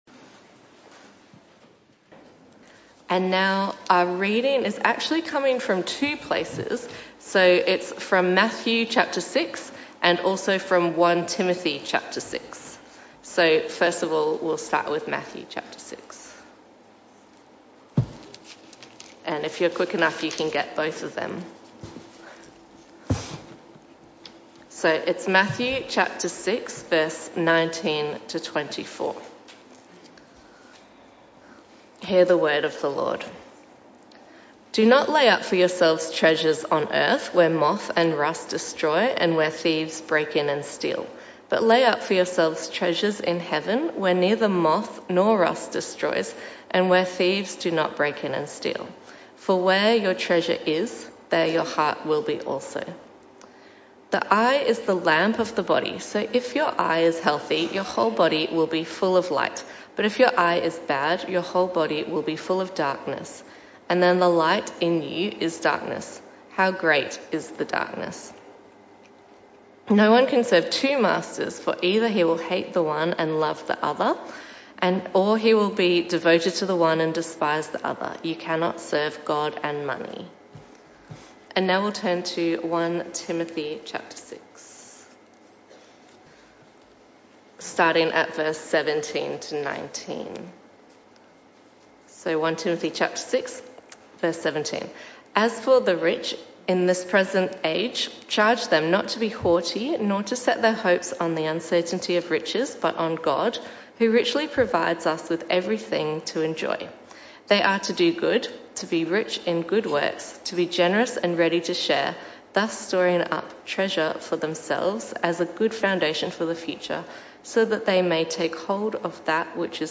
This talk was a one-off talk in the AM Service.